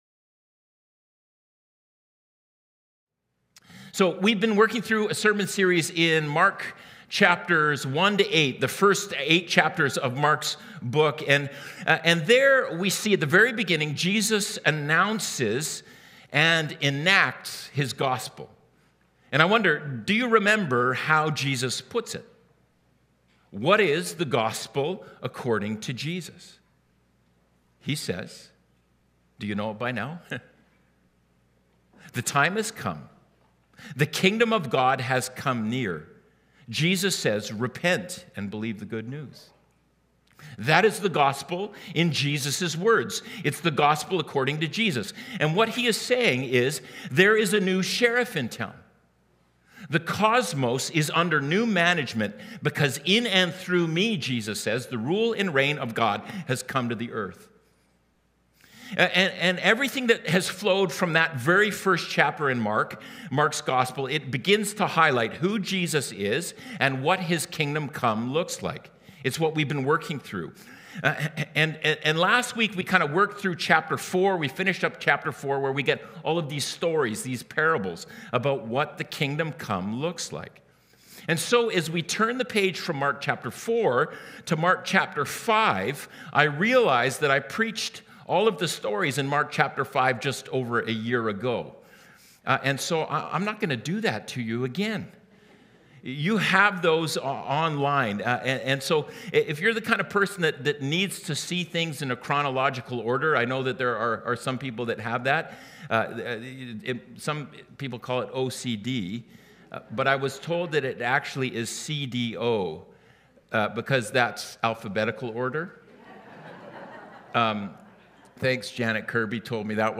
SERMONS | Mission Creek Alliance Church